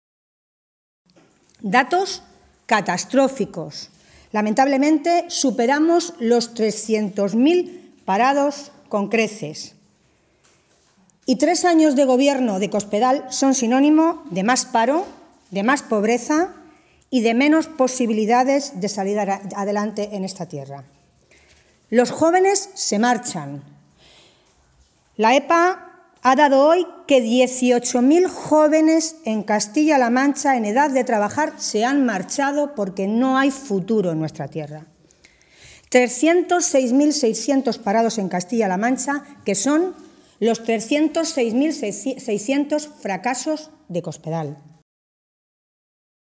Tolón se pronunciaba de esta manera esta mañana, en Toledo, en una comparecencia ante los medios de comunicación en la que aseguraba que, después ya de tres años de legislatura, “se puede decir que Cospedal es la Presidenta de los 300.000 parados”.
Cortes de audio de la rueda de prensa